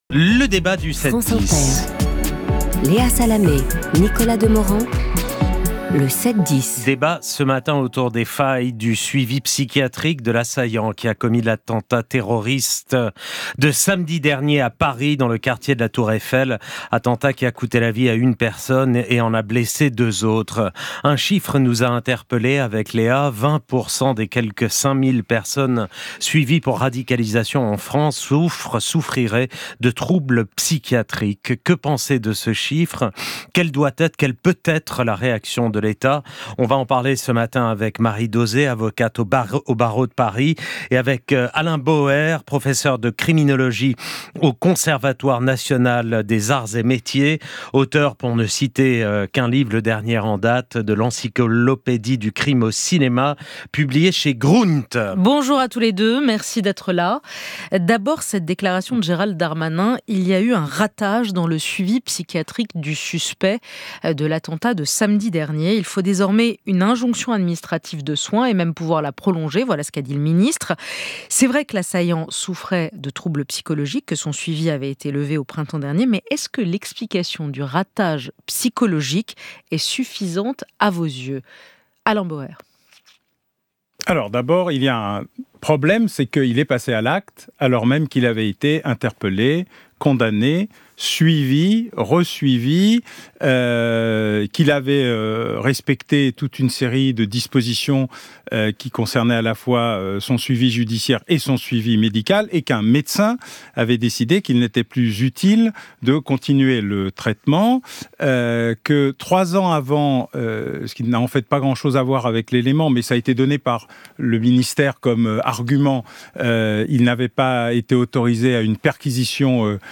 Le débat du 7/10 – Par Nicolas Demorand et Léa Salamé – Diffusé sur France Inter le 6 décembre 2023